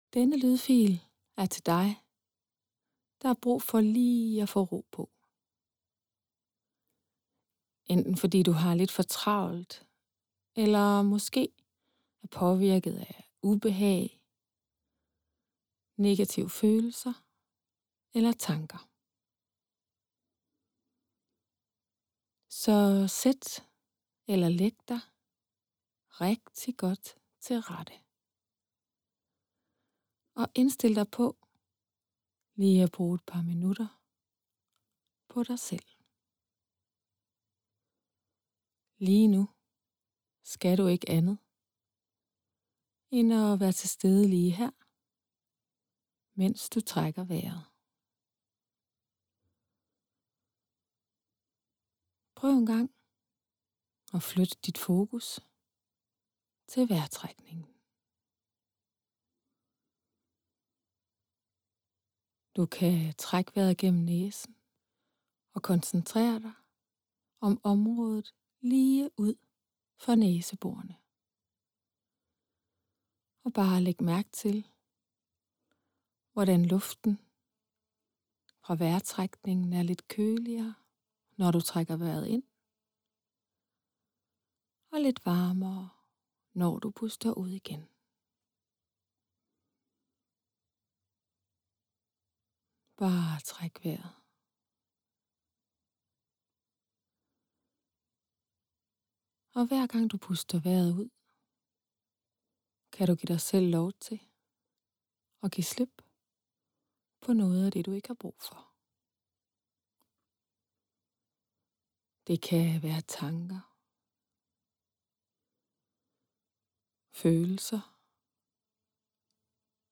Hypnose til graviditet og fødsel
Et øjebliks ro - uden musik
Et-Oejebliks-Ro-Paa-uden-musik-V1.mp3